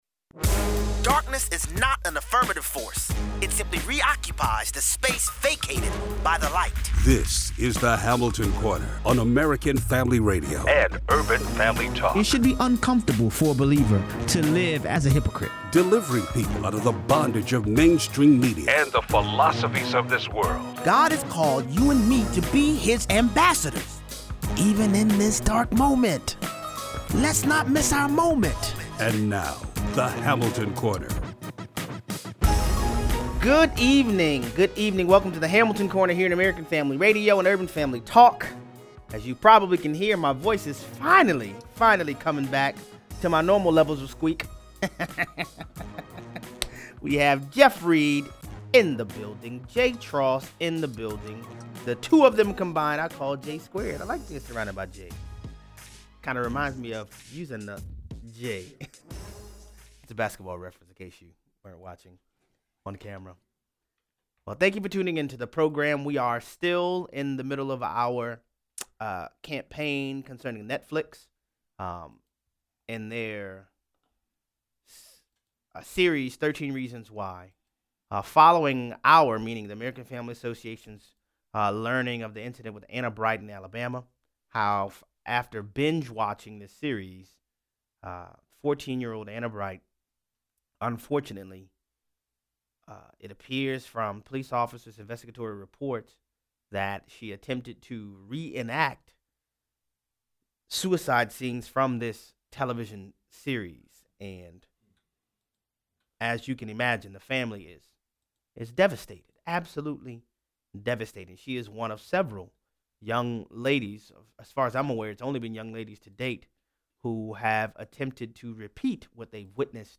David Axelrod warns Democrats that pursuing impeachment might not be such a good idea. Callers weigh in.